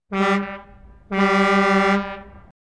Звуки тягача
Водитель грузовика подает сигнал